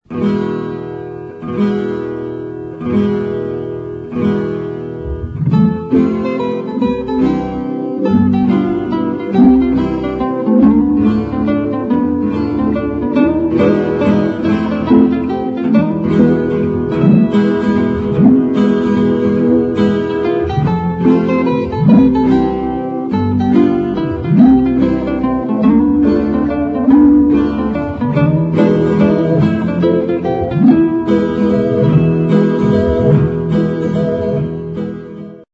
relaxed medium instr.